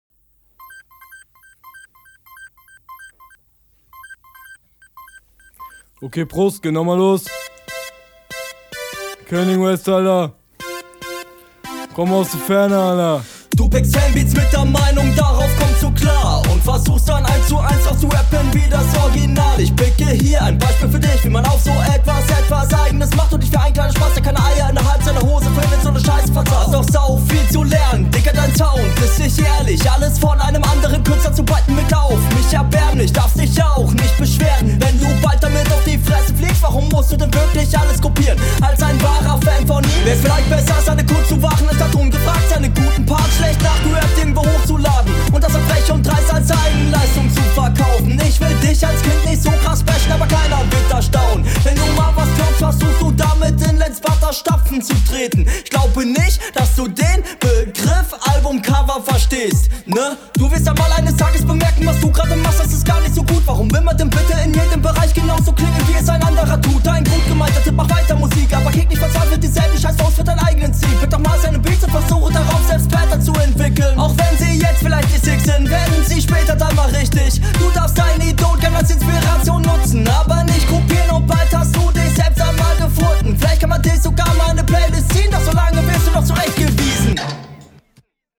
Jo Beat gefällt mir gar nicht.